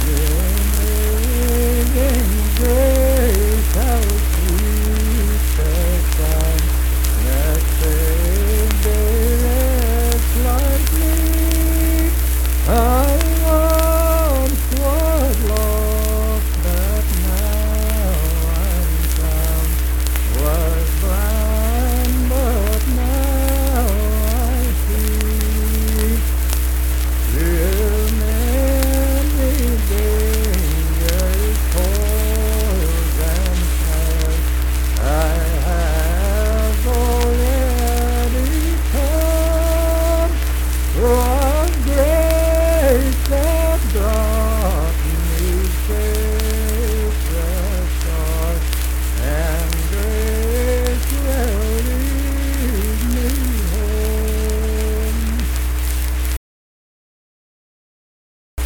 Unaccompanied vocal music performed
Performed in Kanawha Head, Upshur County, WV.
Hymns and Spiritual Music
Voice (sung)